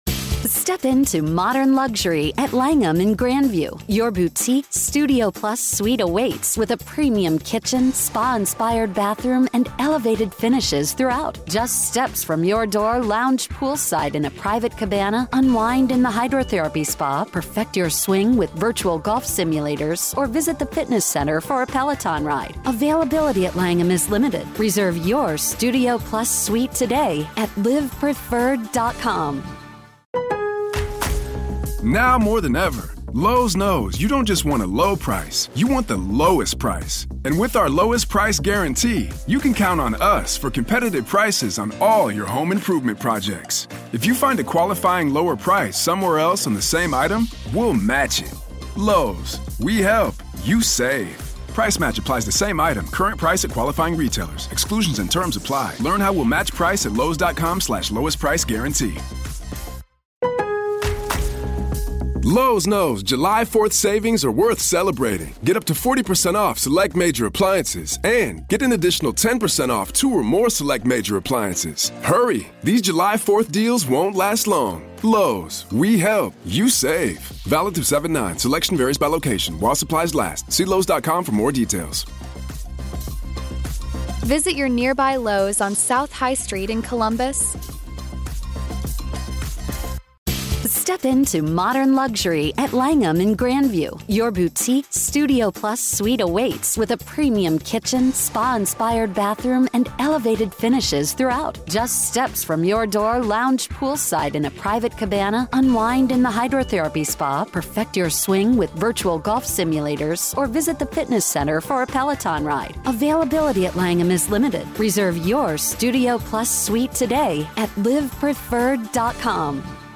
We explore the implications of this new information for the prosecution’s case, the defense’s challenges, and what it could all mean for the trial set to begin in August 2025. Whether you're following the case closely or just tuning in, this conversation is your one-stop-shop for analysis you won’t find anywhere else.